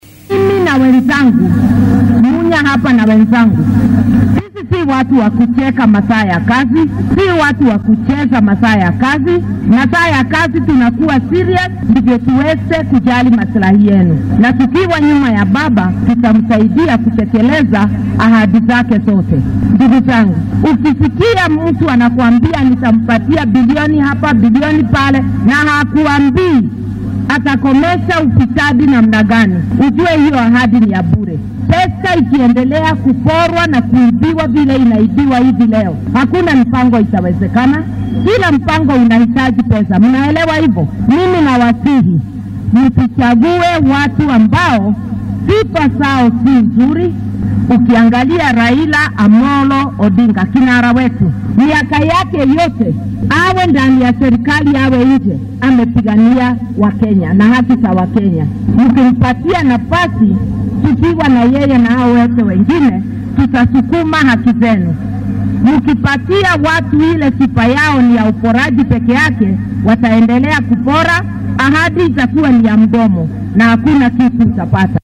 DHAGEYSO:Martha Karua oo isku soo bax ku qabatay Laikipia